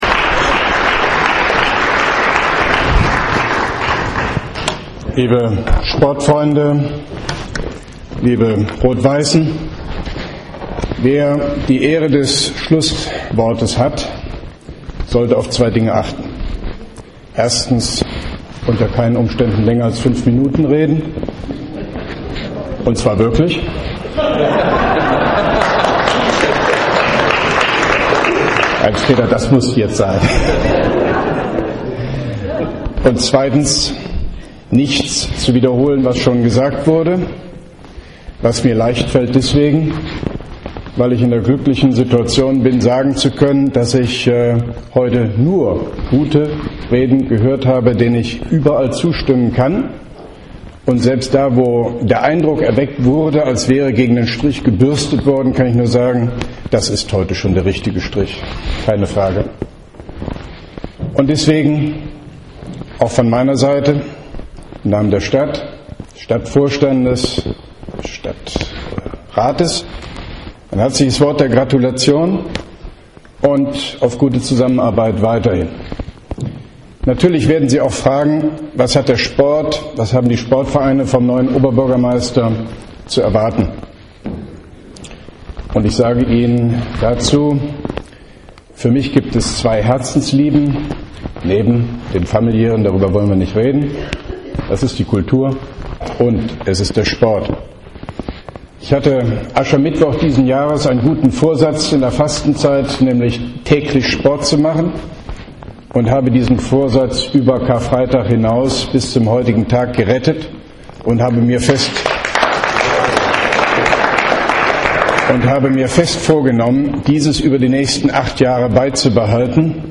Schlussworte des Koblenzer Oberbürgermeisters Hofmann-Göttig beim Festakt 150-Jahre Turn- und Sportfreunde Rot-Weiß, Koblenz 16.05.2010: